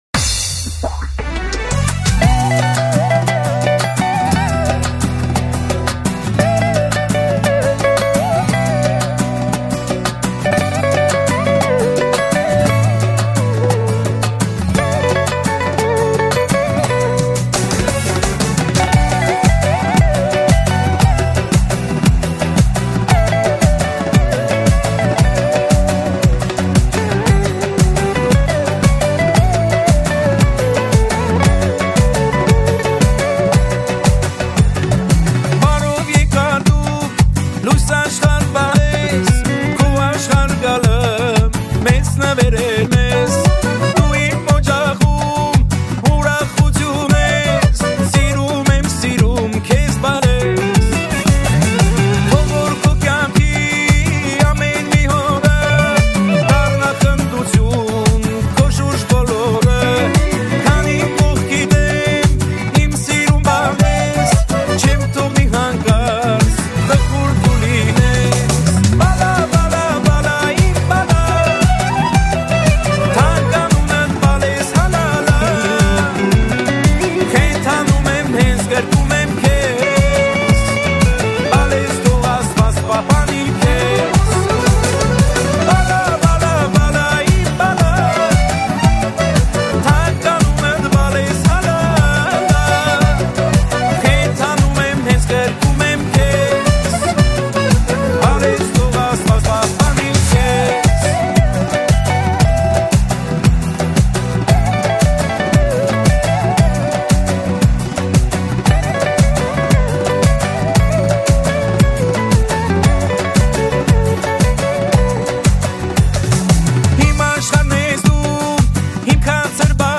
Армянская музыка, Семейный, Erger 2021